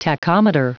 Prononciation du mot tachometer en anglais (fichier audio)
Prononciation du mot : tachometer